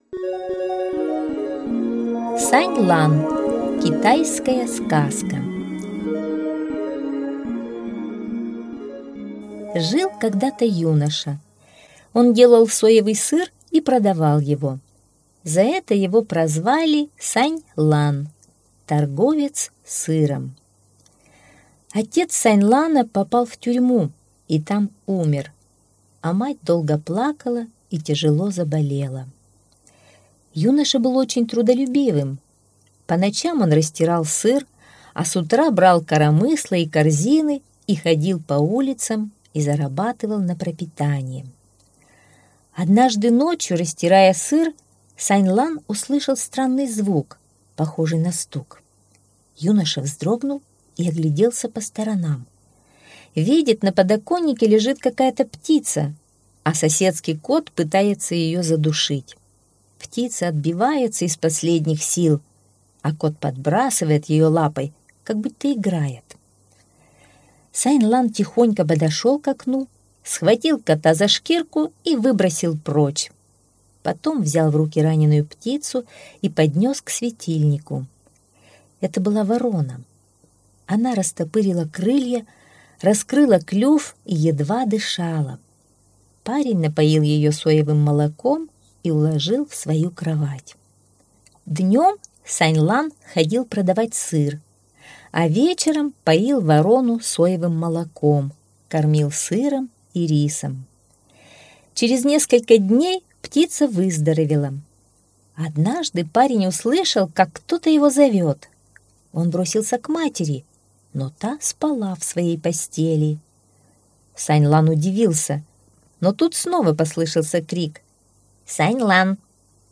Сань-Лан - китайская аудиосказка - слушать онлайн